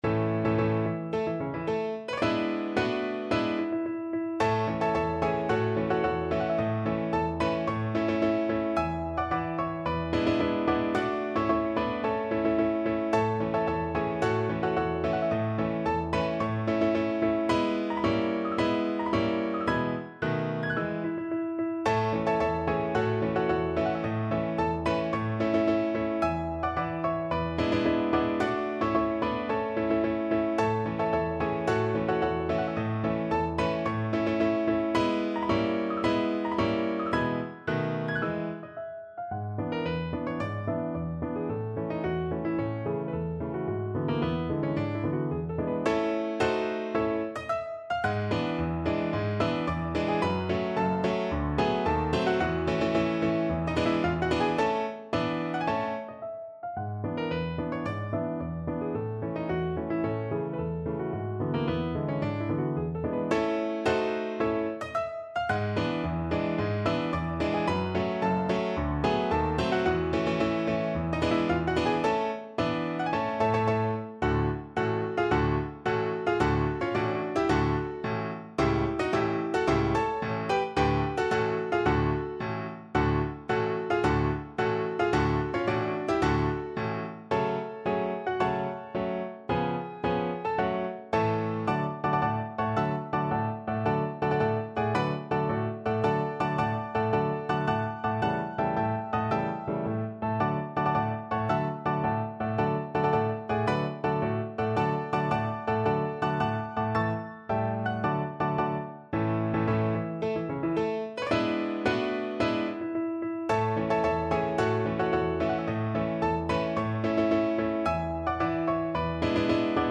Piano version
No parts available for this pieces as it is for solo piano.
March =c.110
2/2 (View more 2/2 Music)
Classical (View more Classical Piano Music)